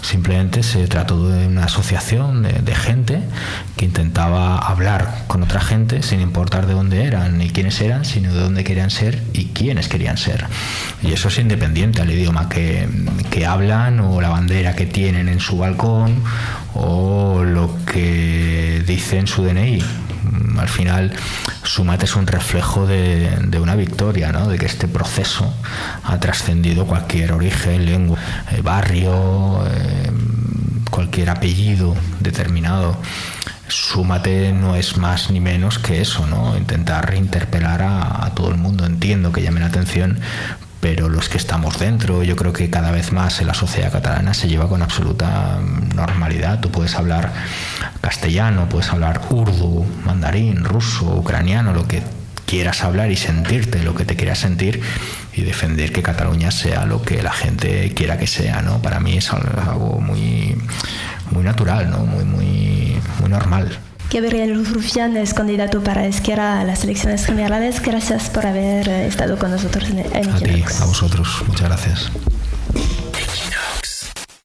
Fragment final de l'entrevista a Gabriel Rufian d'Esquerra Republicana, indicatiu